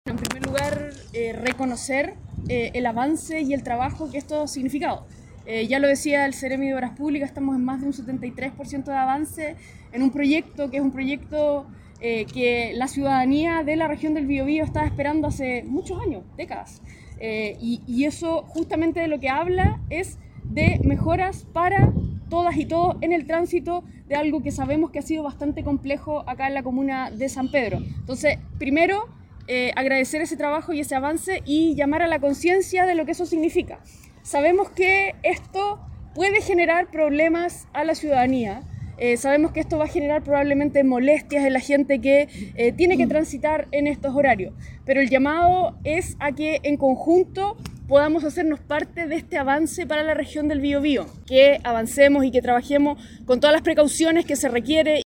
La delegada Daniela Dresdner se refirió a estos trabajos y destacó que “la ciudadanía de la región del Biobío estaba esperando [este proyecto] hace décadas y eso habla de mejoras para todas y todos… Sabemos que esto puede generar molestias en la gente que tiene que transitar en estos horarios, pero el llamado es que podamos hacernos parte en este avance para la región”.